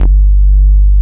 44 MOOG BASS.wav